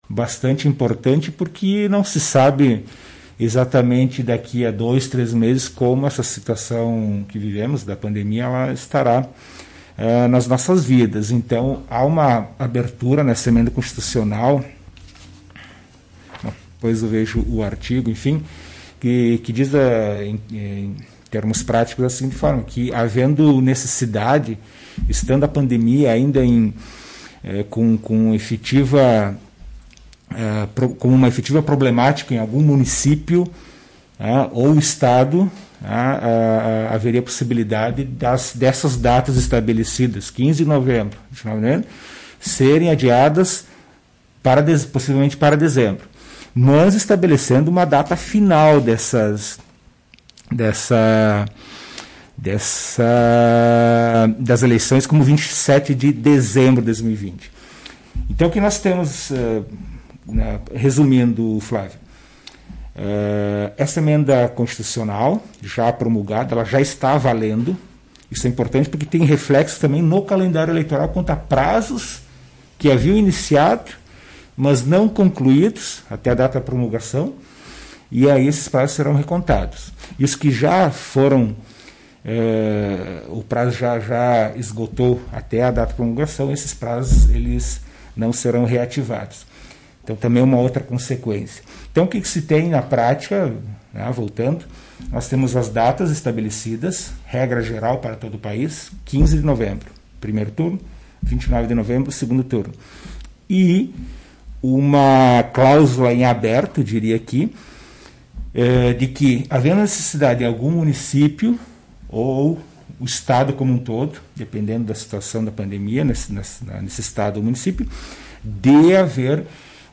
Em entrevista para a Tua Rádio Cacique, O juiz eleitoral Gerson Lira, da 28ª Zona, falou sobre as novas datas.